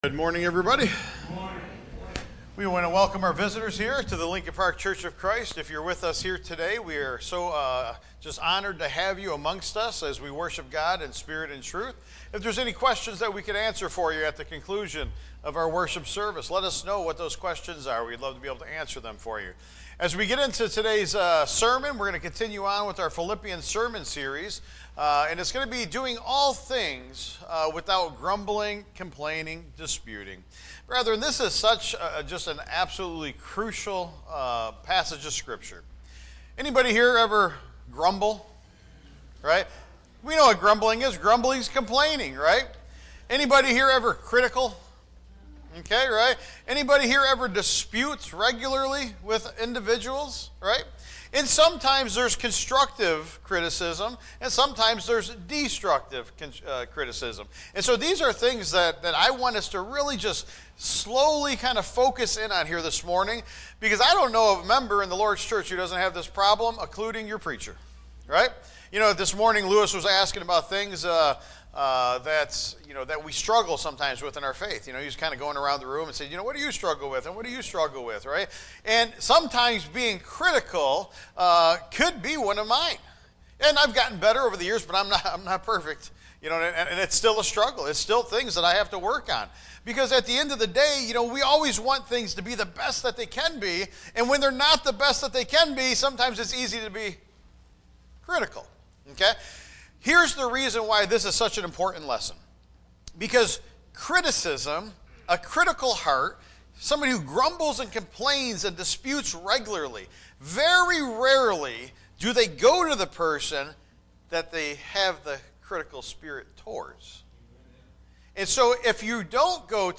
It’s Illogical and Foolish So, what’s the Remedy for a critical and grumbling heart? Cultivate a heart of thankfulness. Look to Jesus. Address concerns constructively. Tagged with sermon Audio (MP3) 17 MB Previous Hold fast to the gospel Next When Overtaken by Sin Part 1